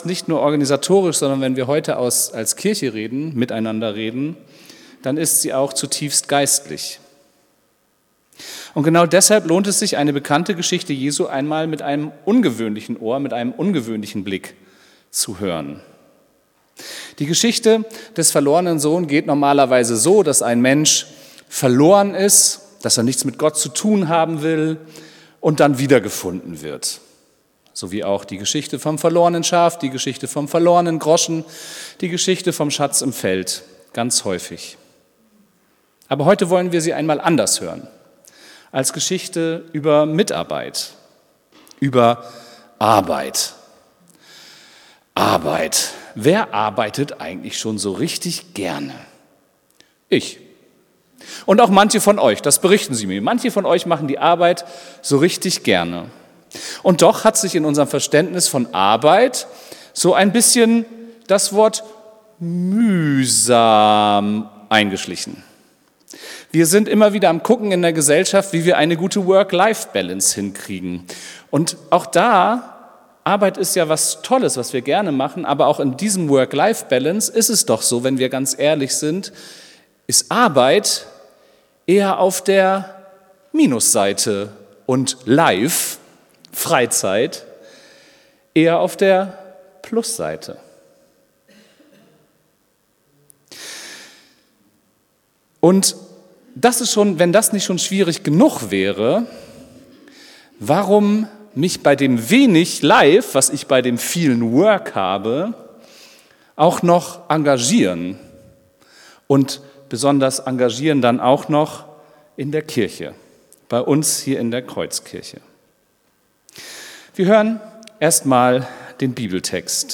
Predigt vom 19.04.2026